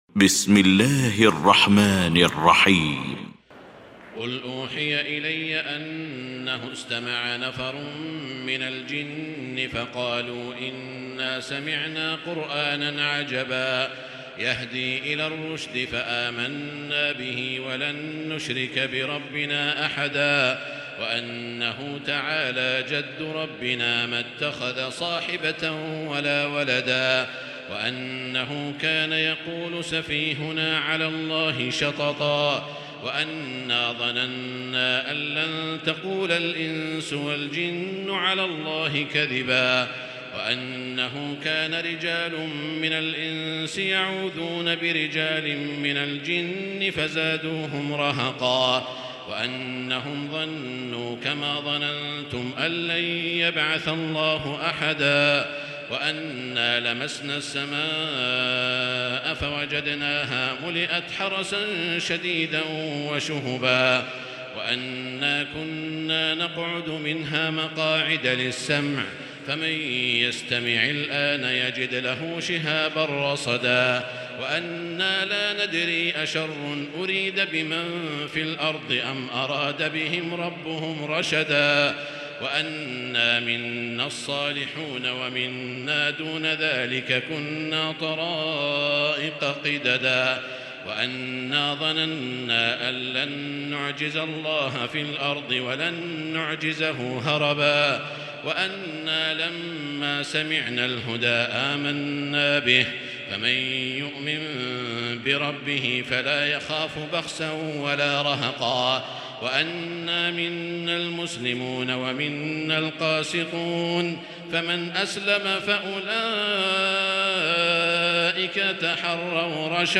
المكان: المسجد الحرام الشيخ: سعود الشريم سعود الشريم الجن